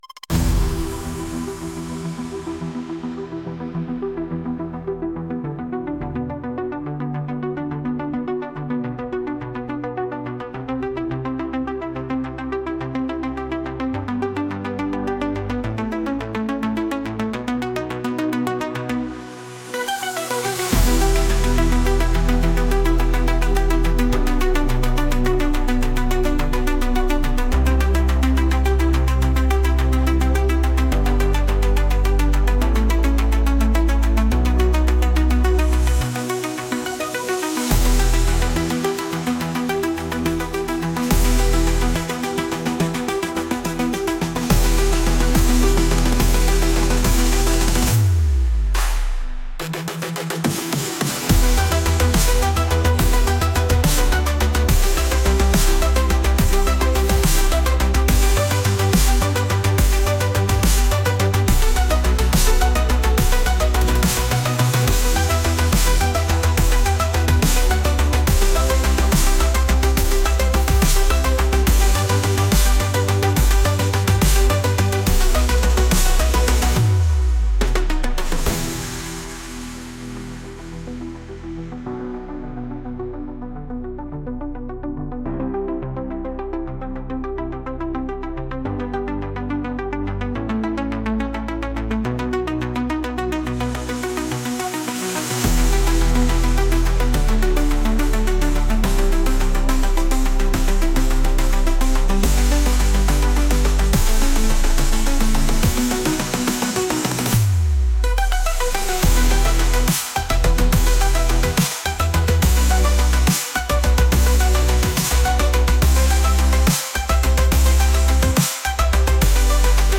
Nostalgic Chiptune Adventure Music